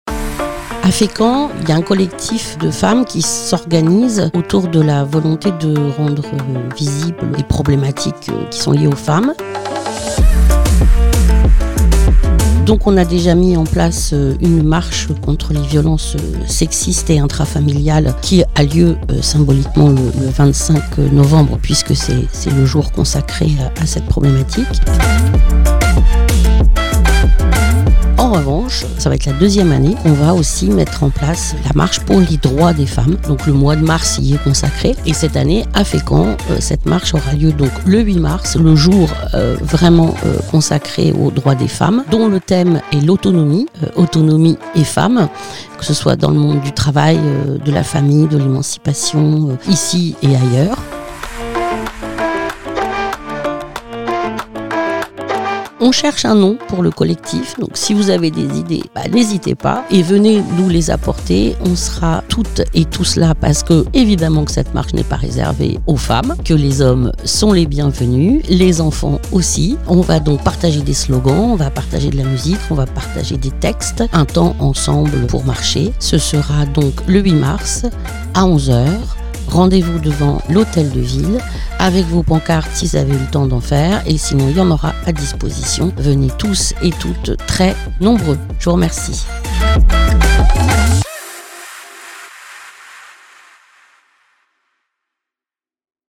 Régulièrement, différentes associations Fécampoises viennent dans nos studios pour enregistrer leurs différentes annonces pour vous informer de leurs activités